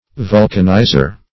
Vulcanizer \Vul"can*i`zer\, n.